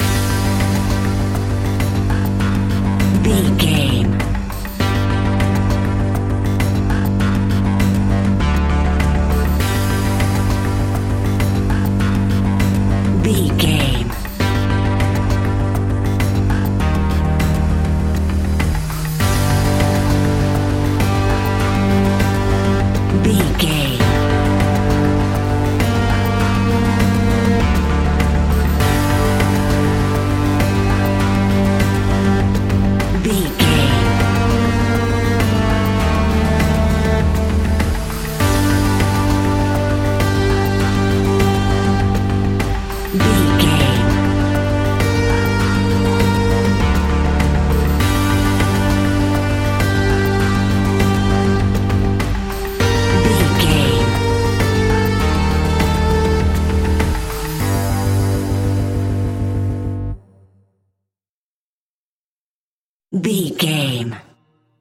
Aeolian/Minor
ominous
dark
haunting
eerie
synthesiser
percussion
drums
bass guitar
strings
ticking
electronic music